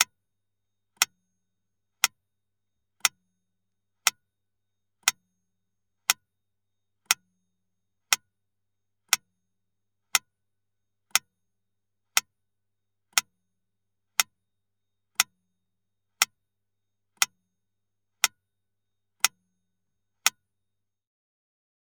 Звуки чисел
Подсчет цифр щелканьем